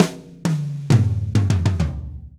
Drumset Fill 07.wav